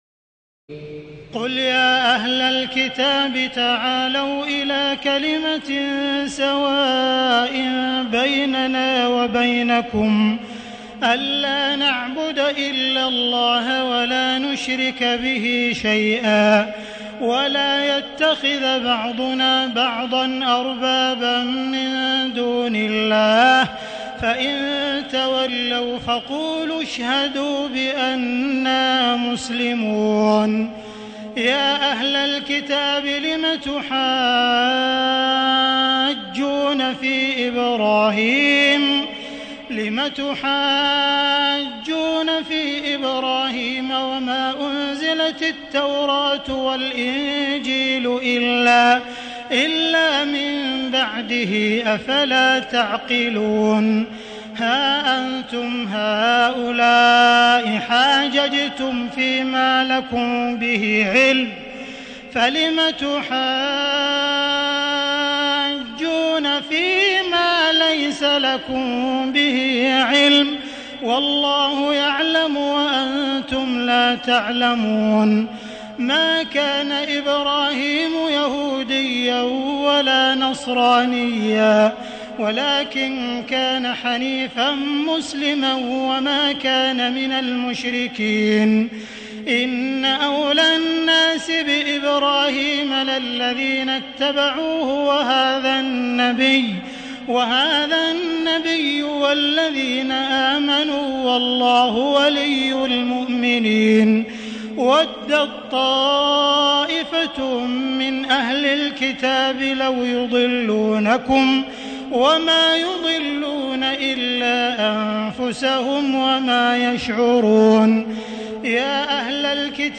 تراويح الليلة الثالثة رمضان 1437هـ من سورة آل عمران (64-151) Taraweeh 3st night Ramadan 1437 H from Surah Aal-i-Imraan > تراويح الحرم المكي عام 1437 🕋 > التراويح - تلاوات الحرمين